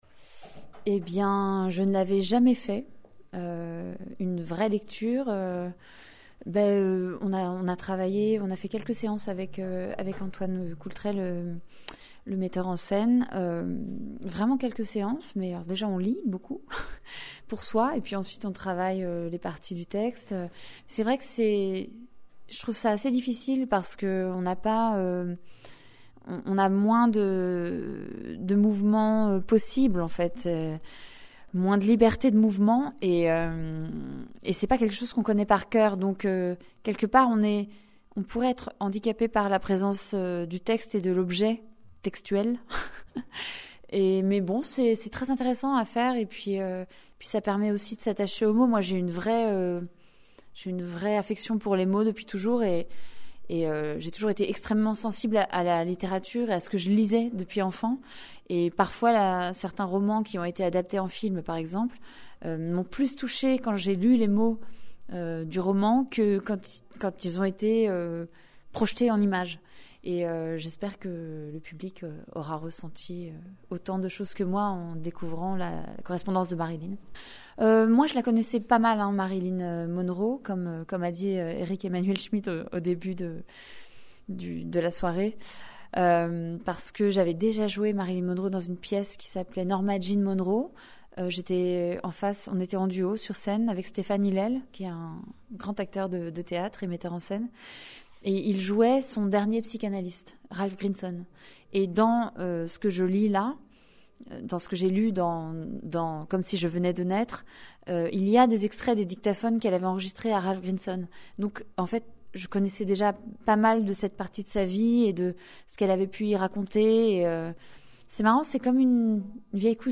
Dans une interview pour la radio du cinéma après cette représentation, Élodie Frégé revient sur l'exercice de la lecture et sur la préparation nécessaire pour ce type de performance.